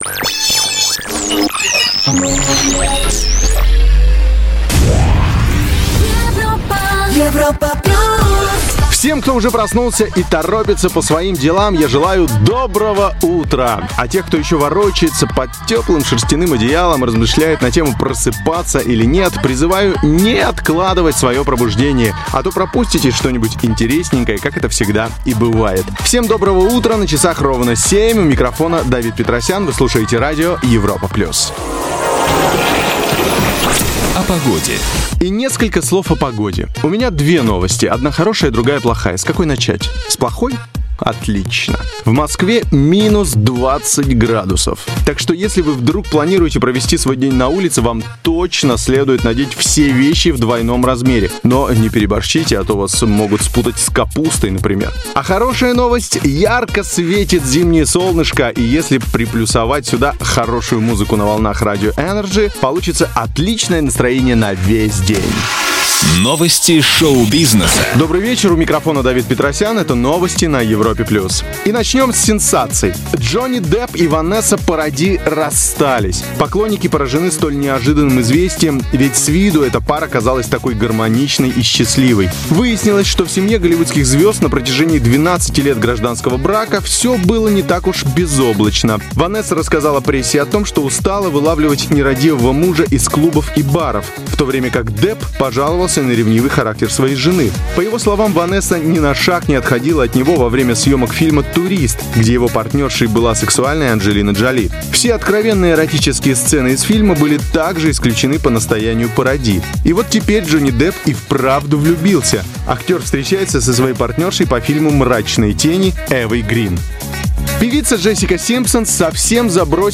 Пол Мужской
Певческий голос Баритон Бас